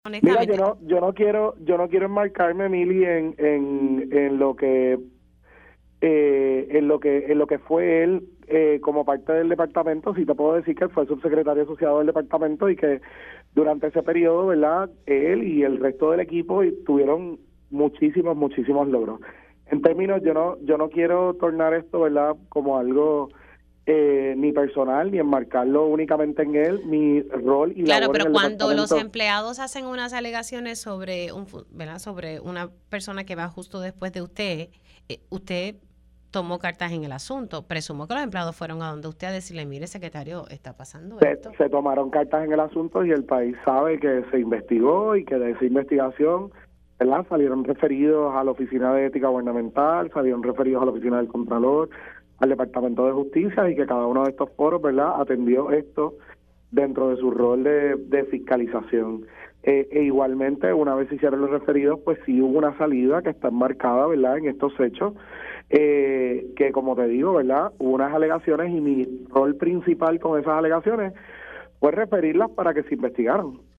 El secretario de Educación, Eliezer Ramos reconoció en Pega’os en la Mañana que el ahora senador por el distrito de Carolina, Héctor Joaquín Sánchez salió del departamento tras denuncias de corrupción en su contra.